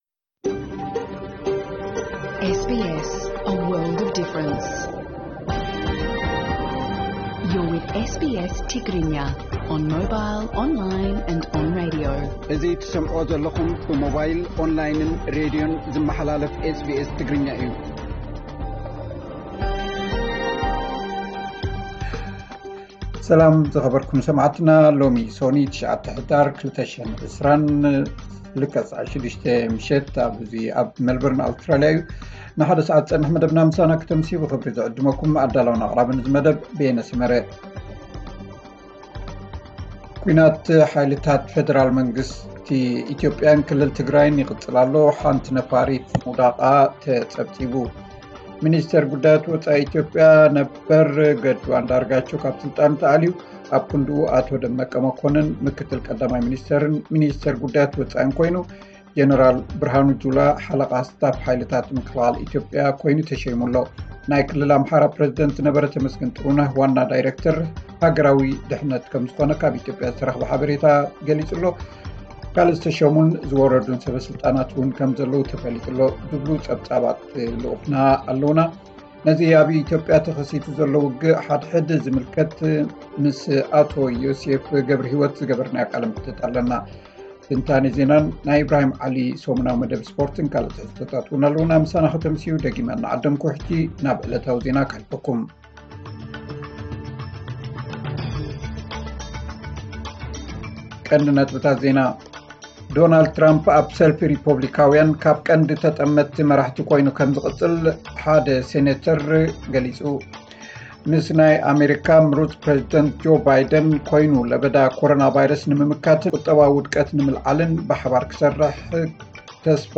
ዕለታዊ ዜና 9 ሕዳር 2020